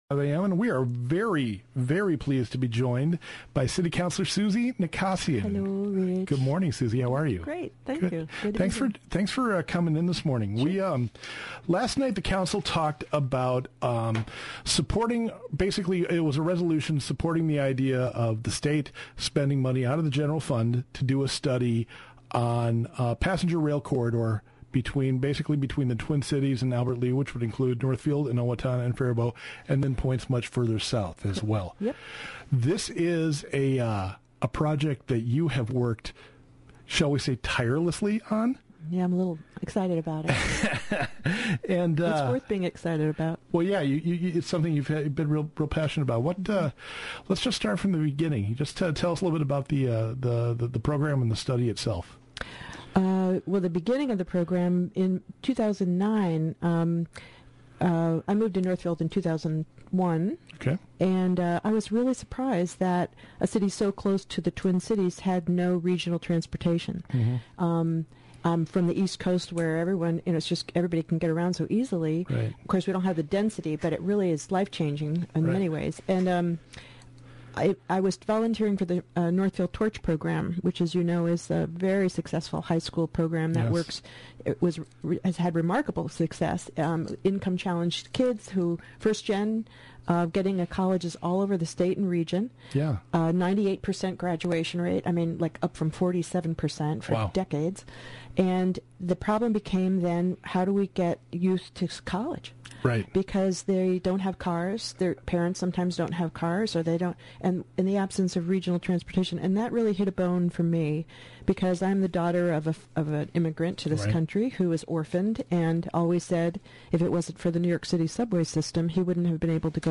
Northfield City Councilor Suzie Nakasian talks about the process underway for passenger rail from the Twin Cities to Albert Lea. The City Council has passed a resolution to ask the State of MN to study what would be involved in terms of infrastructure.